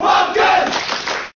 Fox_Cheer_Japanese_SSBM.ogg